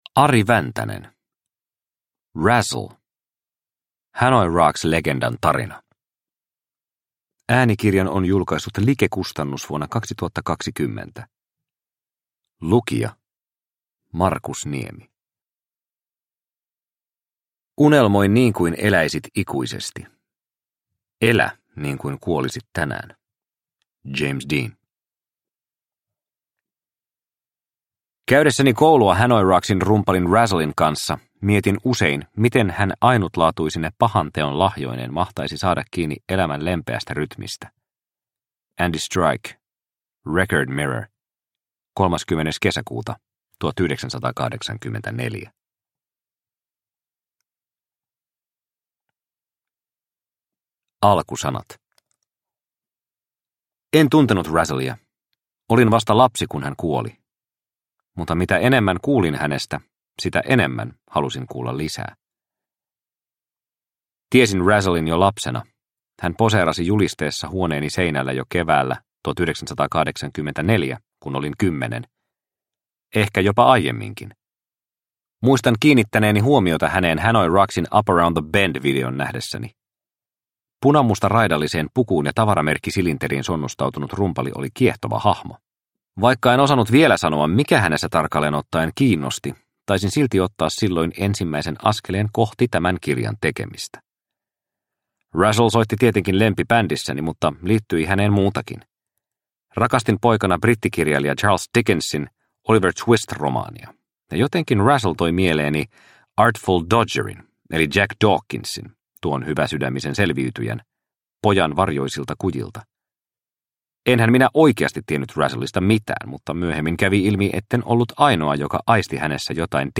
Razzle – Ljudbok